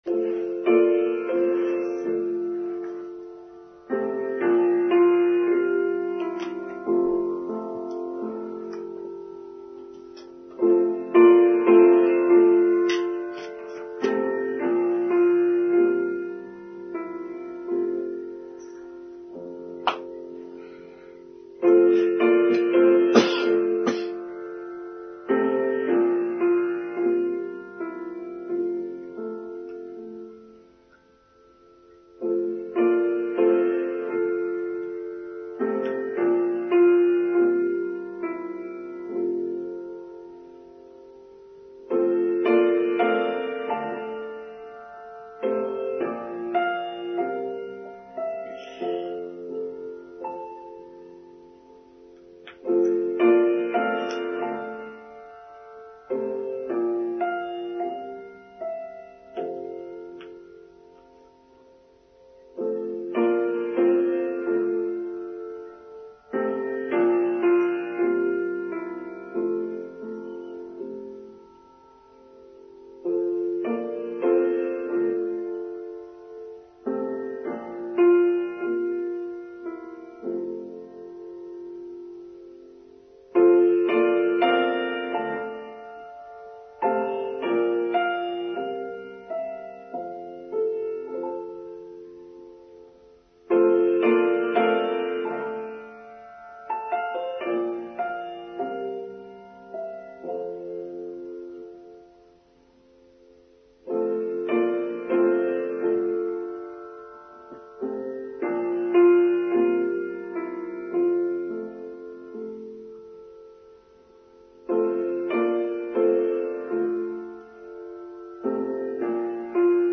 New Year 2023: Online Service for Sunday 1st January 2023